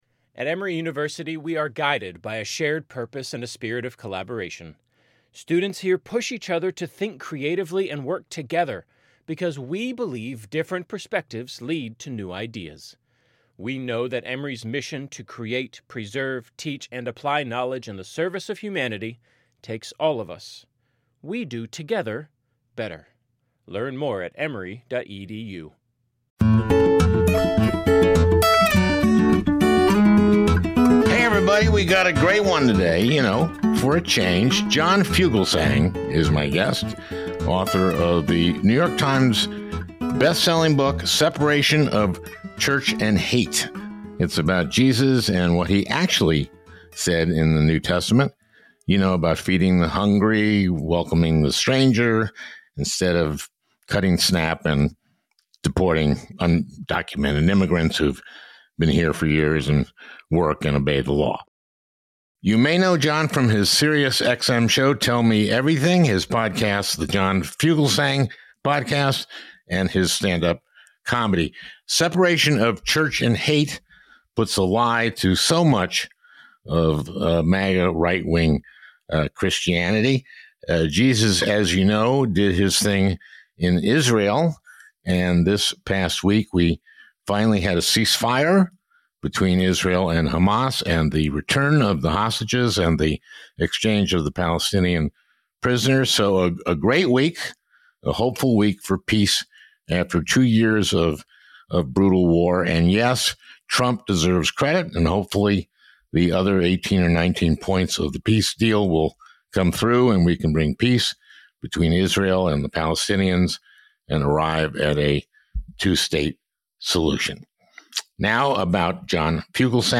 We hope this interview can help you engage with right-wing Christians in a civil way by pointing to actual scripture, which might help them see the contradictions and lies that their party embraces.